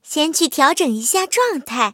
M22蝉小破修理语音.OGG